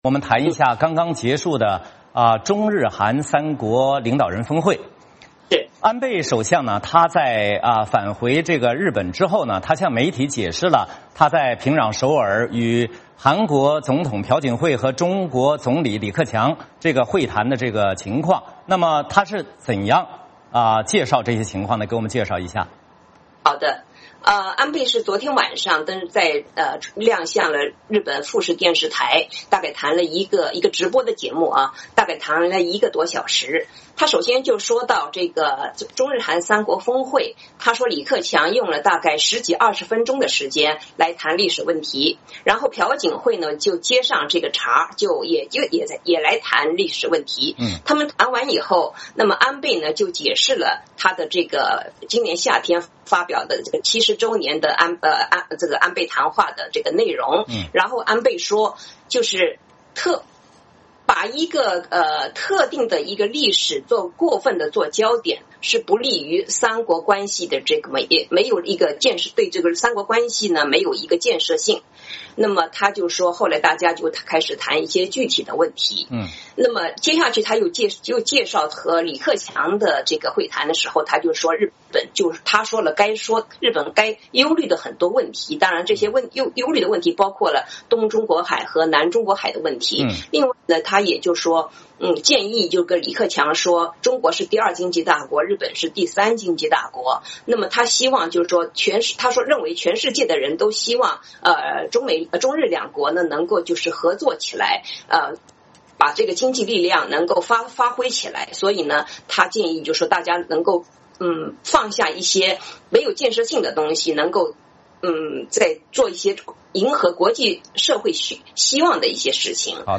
VOA连线：安倍晋三谈中日韩领导人峰会